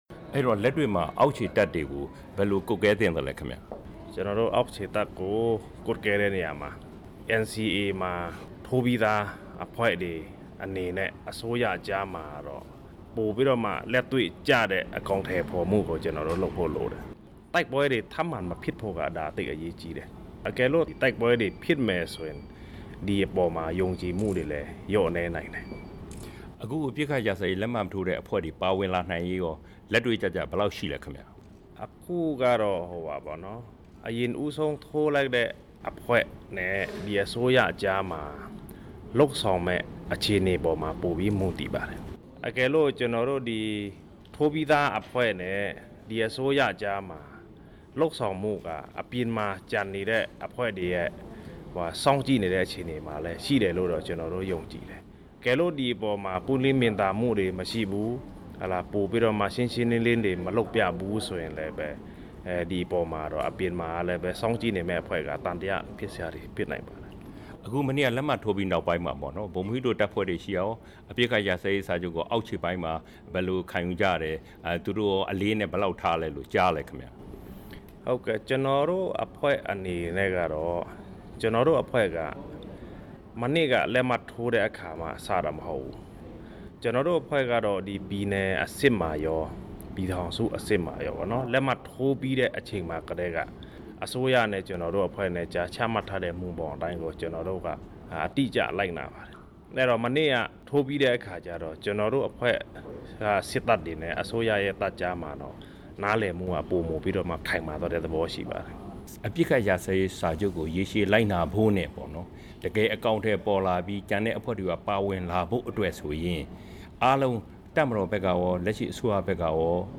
တွေ့ဆုံမေးမြန်းချက်